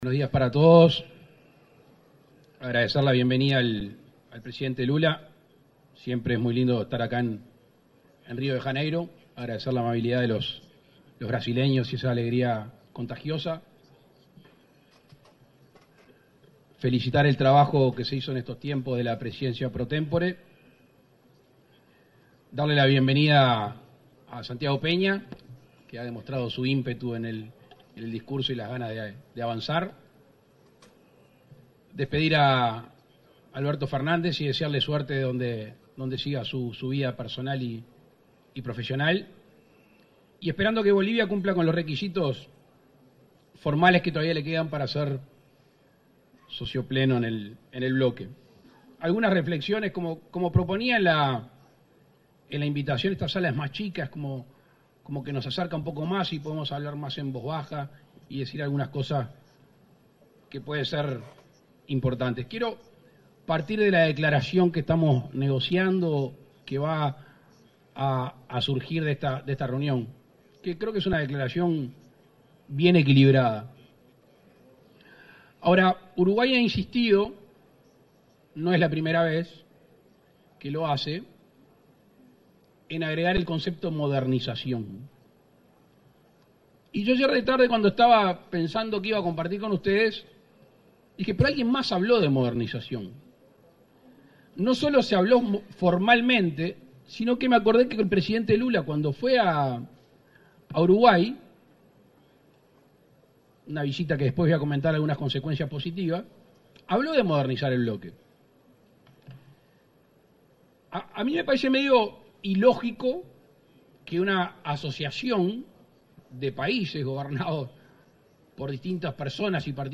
Palabras del presidente Luis Lacalle Pou
El presidente Luis Lacalle Pou participó este jueves 7, en Brasil, de la LXIII Cumbre de Jefes de Estado del Mercosur y Estados Asociados.